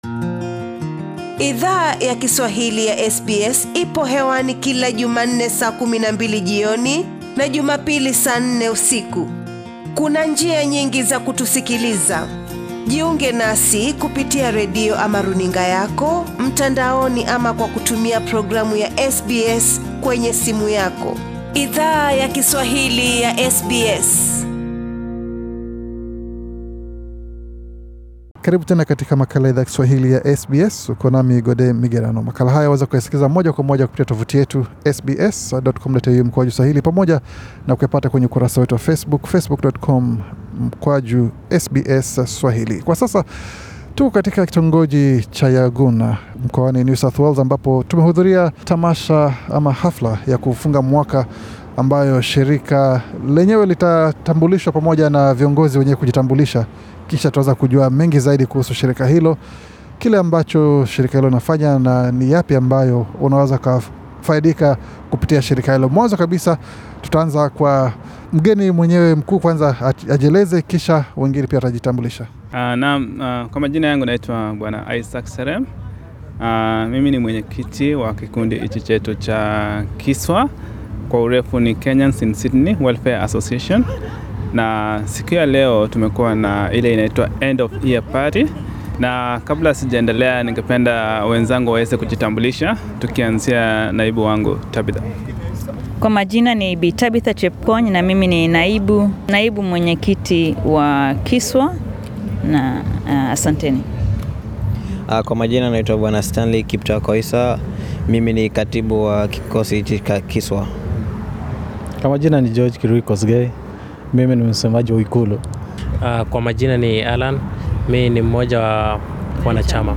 Wanachama wa kamati ya KISWA, wali eleza SBS SWahili kuhusu, baadhi ya miradi ambayo wame fanya mwaka huu wa 2018, pamoja nakuzungumzia miradi ambayo ime ratibiwa kufanywa katika mwaka wa 2019.